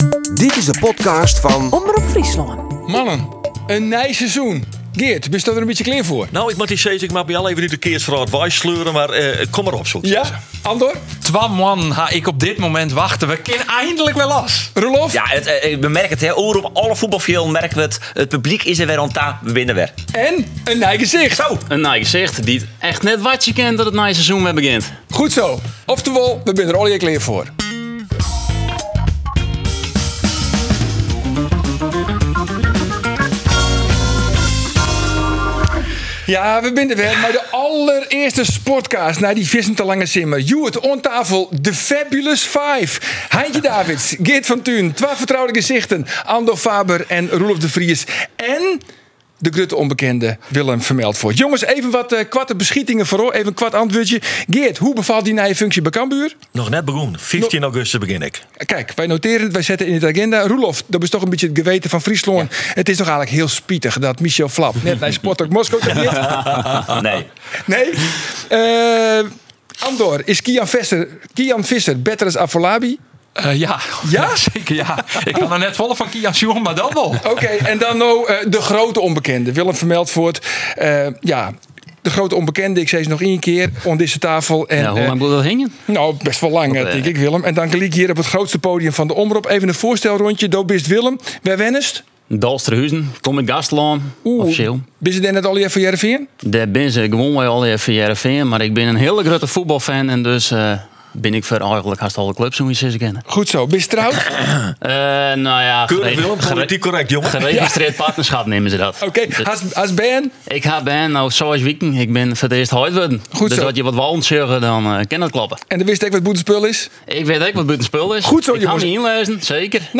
Yn de SportCast prate de sportferslachjouwers fan Omrop Fryslân dy by oer it betelle fuotbal yn Fryslân. Alles oer sc Hearrenfean en SC Cambuur wurdt troch ús fêste sportploech behannele.